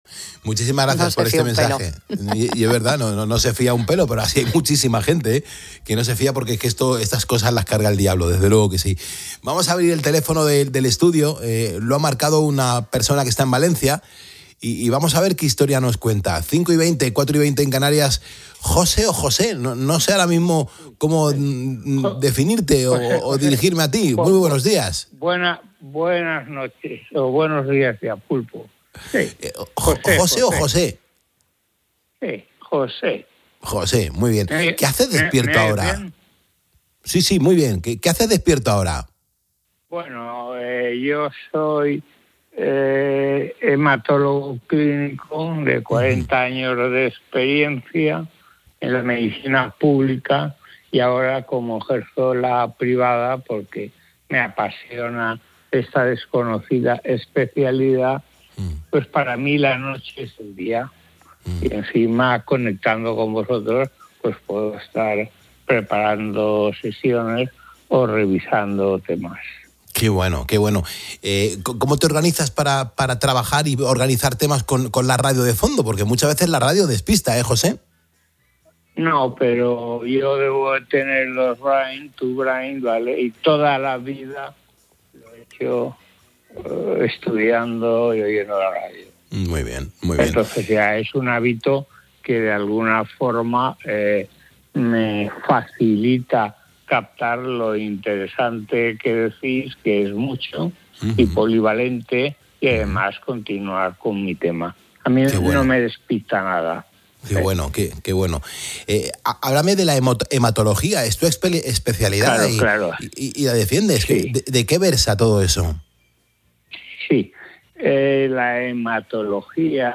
un oyente hematólogo con 40 años de experiencia